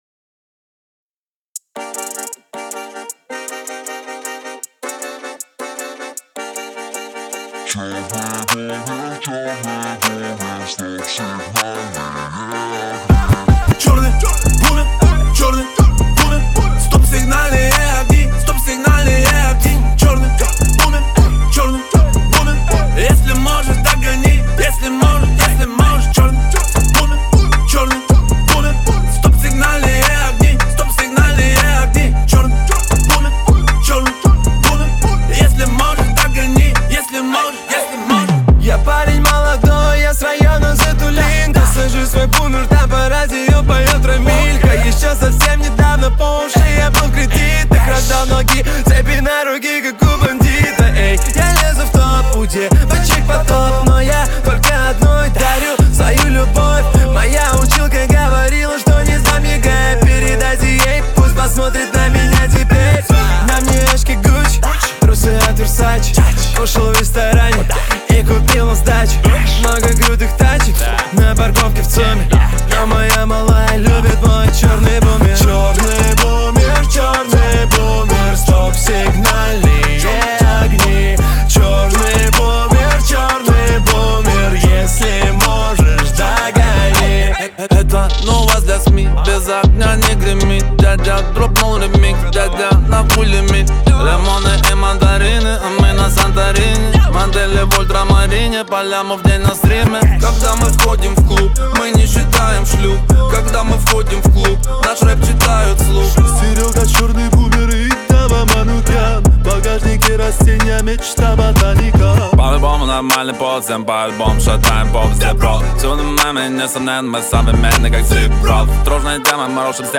Категории: Русские песни, Рэп и хип-хоп.